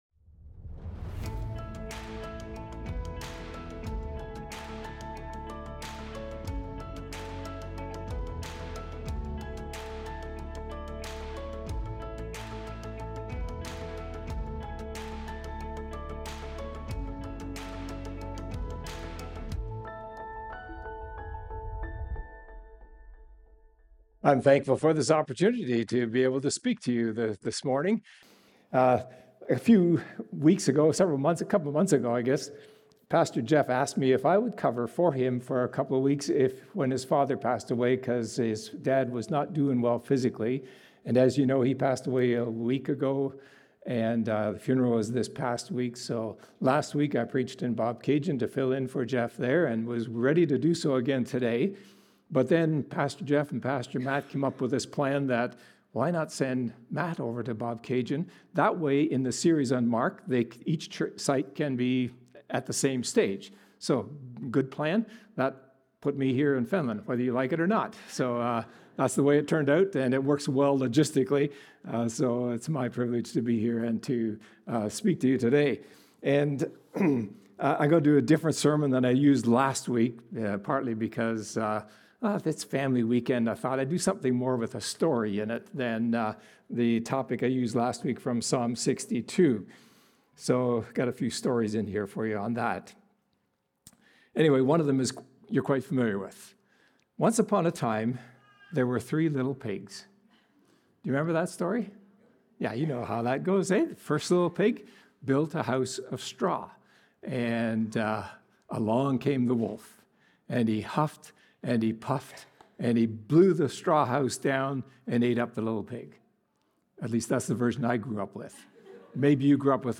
Recorded Sunday, February 15, 2026, at Trentside Fenelon Falls.